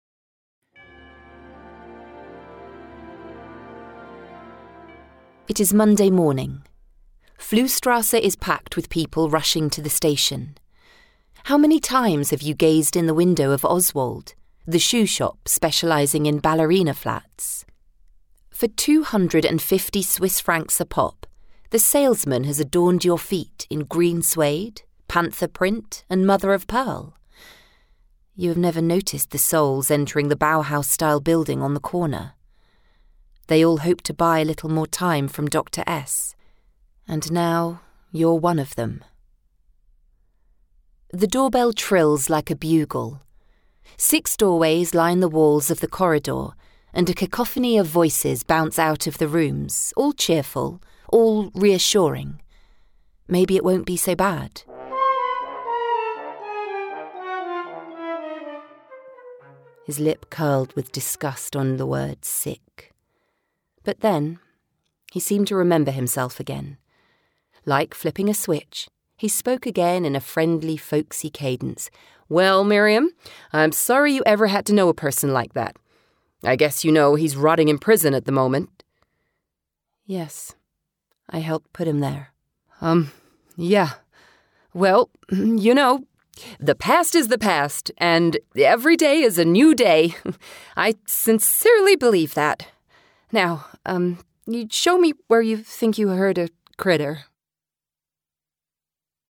English (British)
Audiobooks
Rode NT1A condenser microphone
Mezzo-SopranoSoprano
CaringWarmMotherlyHumorousEnthusiasticFriendlyGirlySmoothNatural